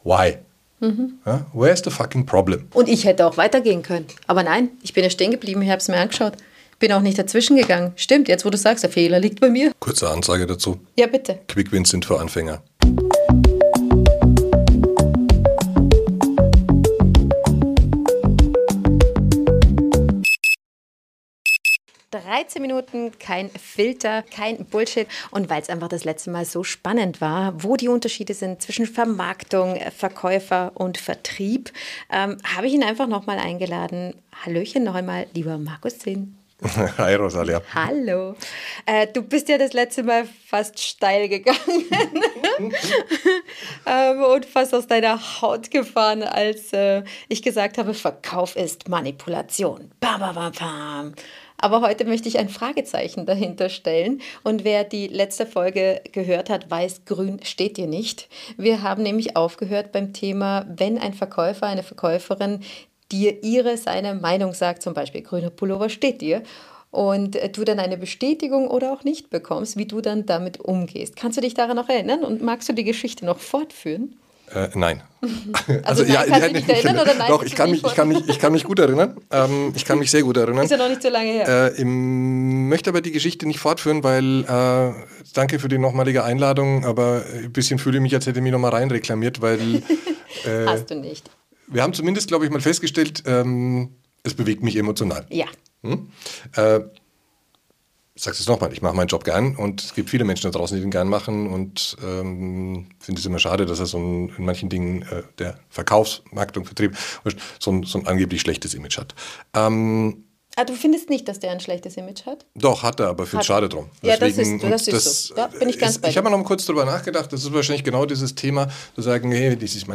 Diskussion